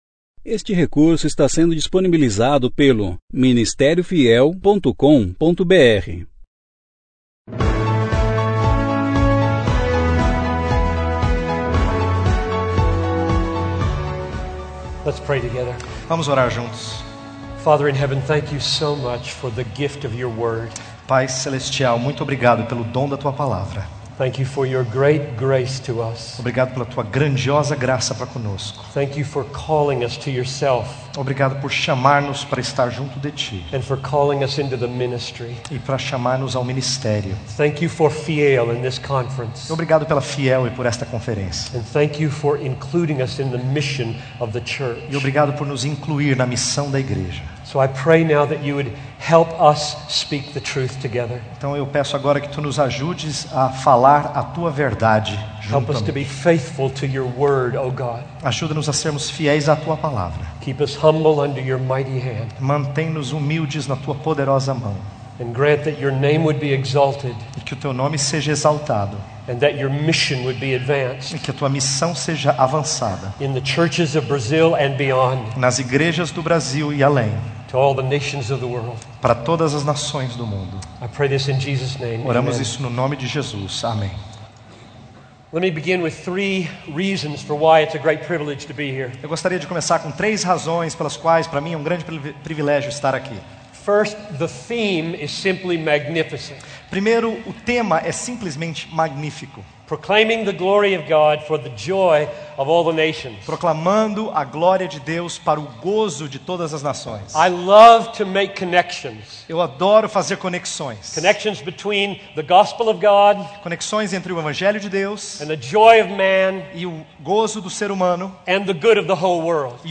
Conferência: 27ª Conferência Fiel para Pastores e Líderes Tema: Evangelização e Missões – Is 52.7 Ano: 2011 Mensagem: Santificado Seja o Teu Nome: Entre Todos os Povos do Mundo Preletor: John Piper